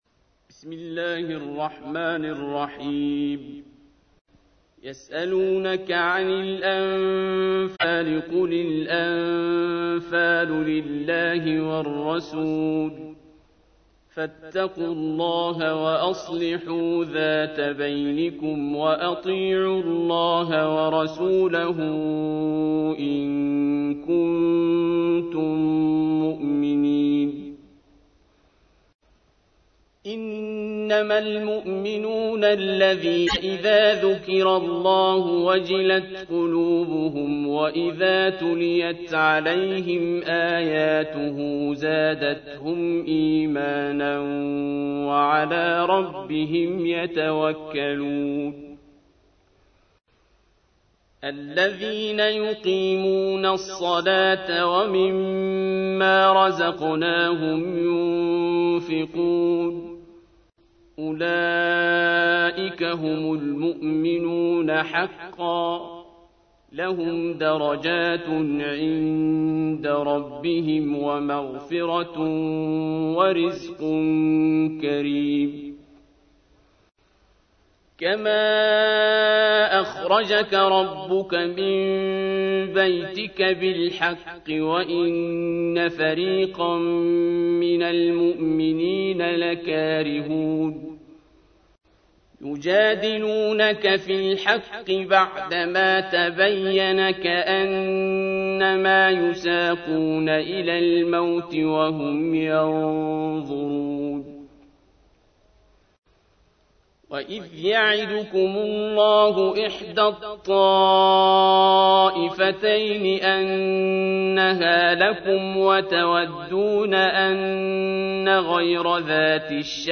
تحميل : 8. سورة الأنفال / القارئ عبد الباسط عبد الصمد / القرآن الكريم / موقع يا حسين